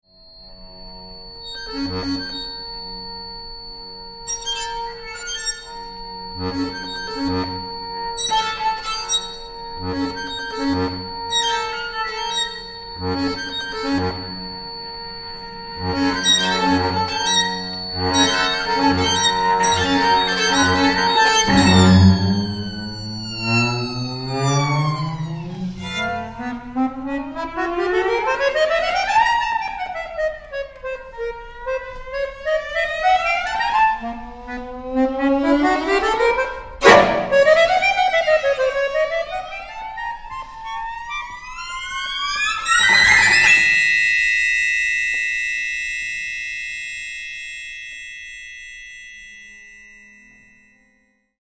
Au programme également, De l’épaisseur de Philippe Leroux par L’Itinéraire.
violon
alto
violoncelle
accordéon